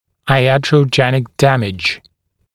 [aɪˌætrəuˈdʒenɪk ‘dæmɪʤ][айˌэтроуˈдженик ‘дэмидж]ятрогенный вред